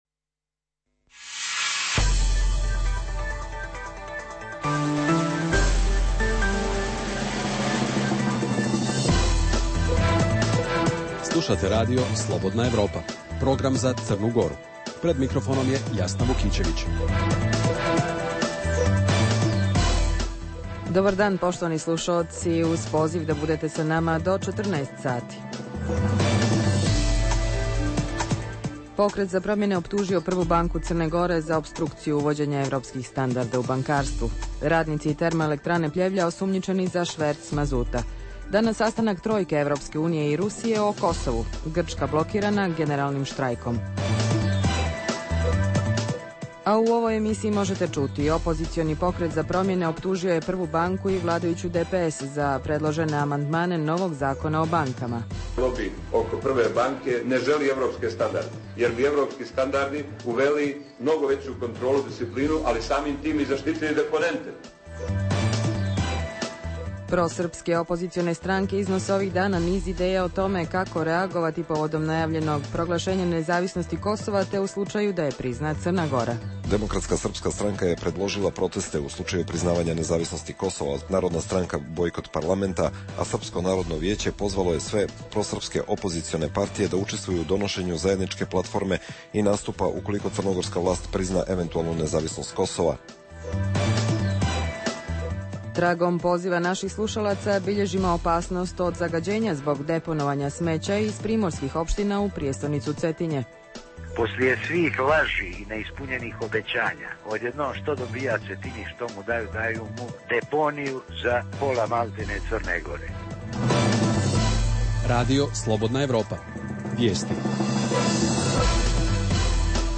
Pokret za promjene optužuje Prvu banku CG za sprječavanje uvođenja evropskih standarda u bankarstvo Reportaža iz Bijelog Polja o protestu zubara zbog reorganizacije stomatološke službe Srpska opozicija u CG predlaže da CG prizna nezavisno Kosovo kada i Srbija